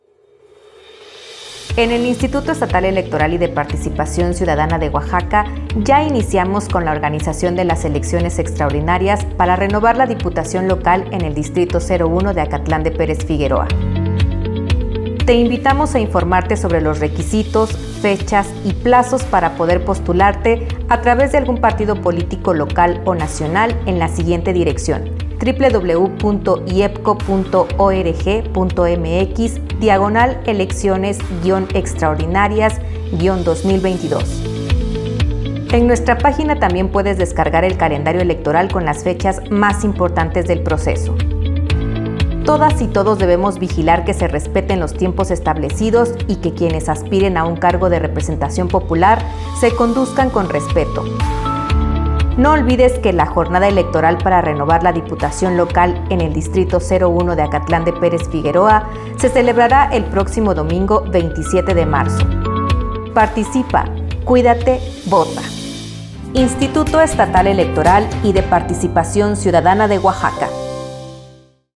Spot Diputación Local Dtto. 01